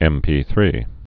(ĕmpē-thrē)